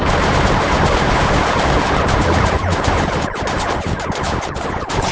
"text": "two starships are fighting in space with laser cannons",
two starships are fighting in space with laser cannons.wav